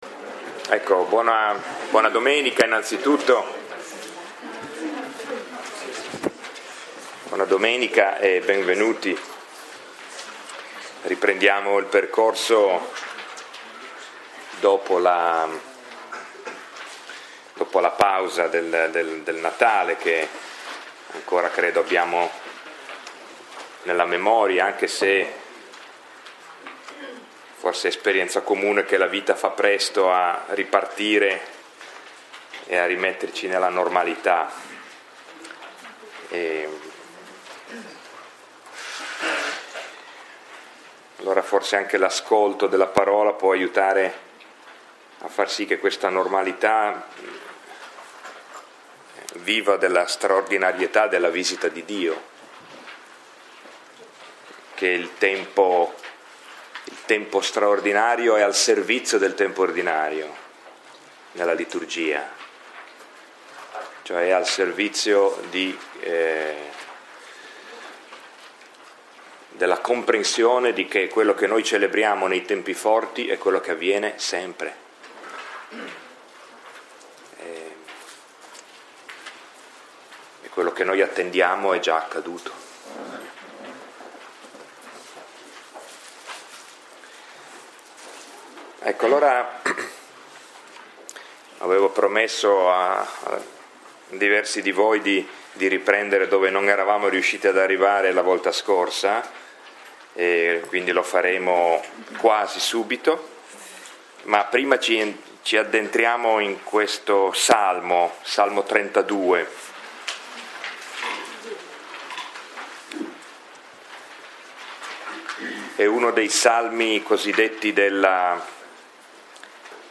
Lectio 4 – 17 gennaio 2016